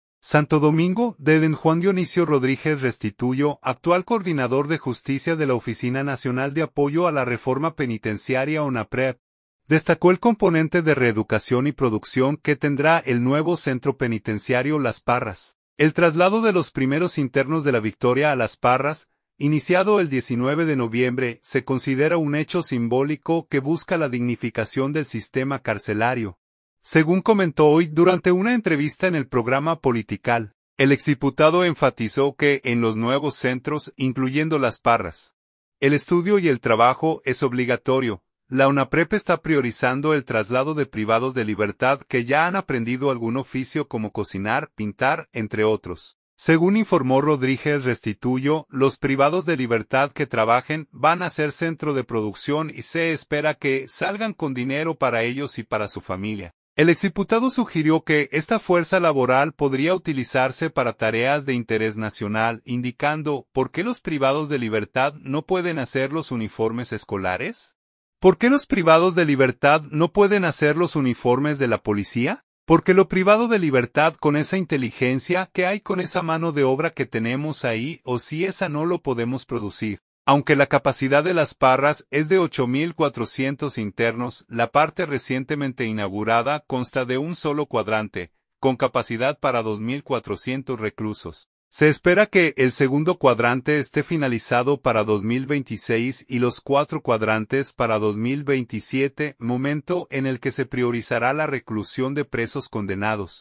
El traslado de los primeros internos de La Victoria a Las Parras, iniciado el 19 de noviembre, se considera un hecho simbólico que busca la dignificación del sistema carcelario, según comentó hoy durante una entrevista en el programa Politikal.